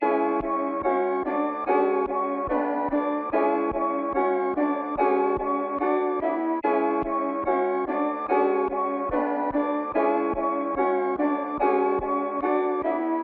铃鼓循环 " 铃鼓循环 145bpm
描述：自录Tambourine Loop 145 BPM
Tag: 循环 BPM 145 铃鼓 另类